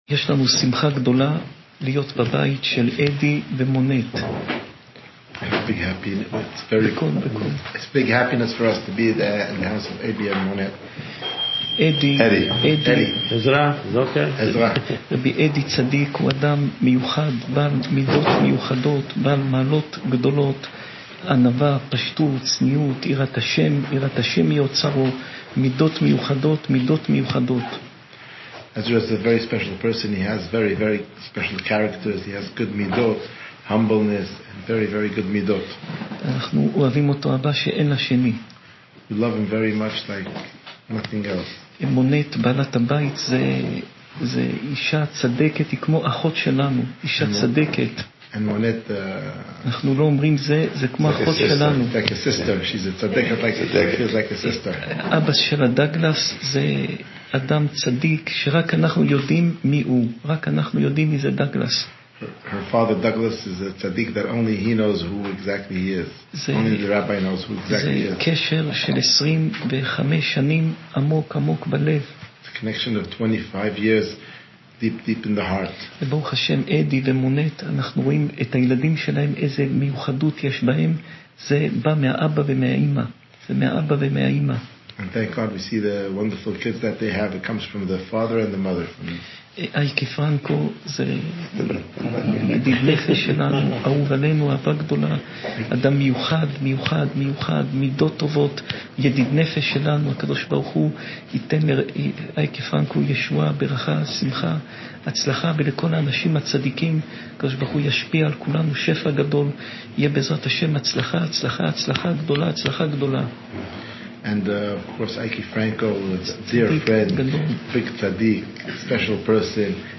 שעור תורה